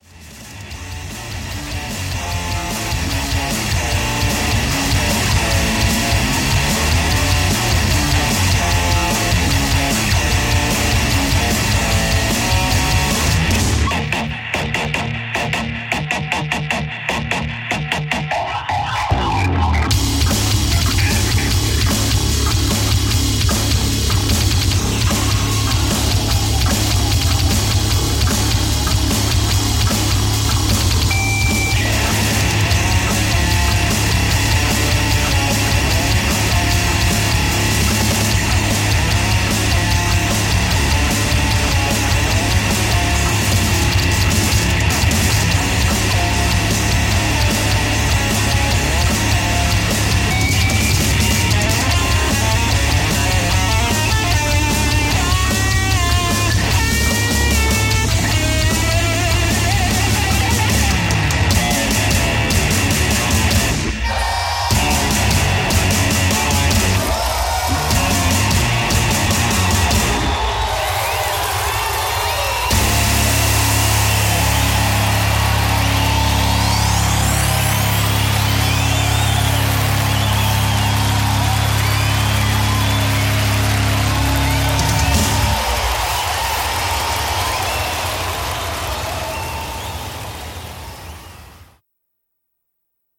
Byzantine tuning.
Byzantine scale example used in a band situation.mp3